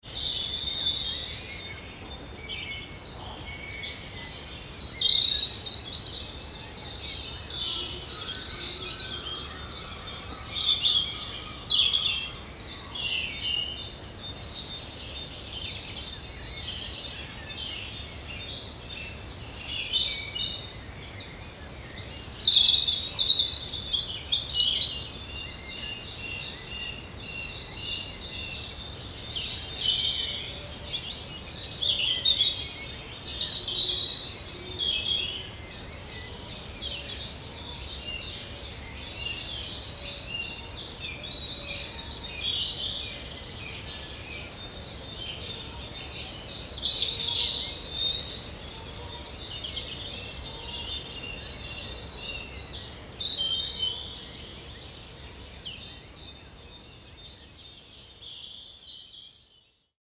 Photographic Sound Installation
Each installation is our own re-experience of a place in Cornwall that we have become familiar with through wild camping and cycling.
Bluebell Woods dawn chorus (stereo edit)